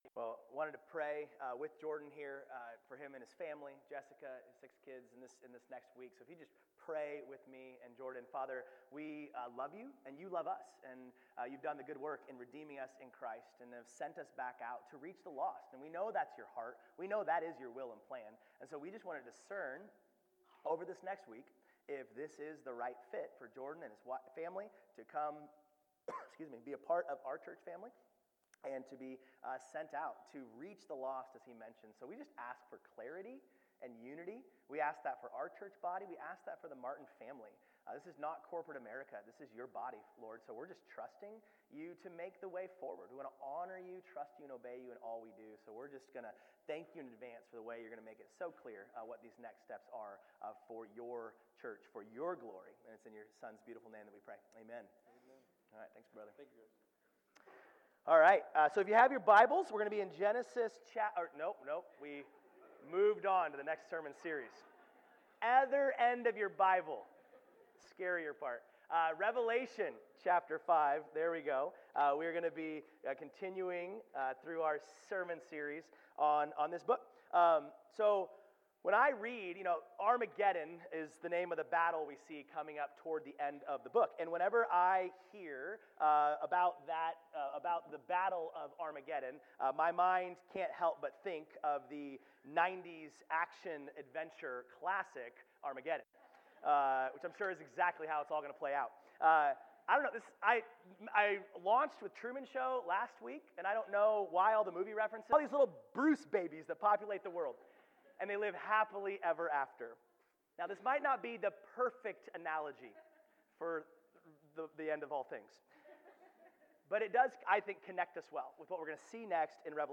Sermons | Peninsula Grace Church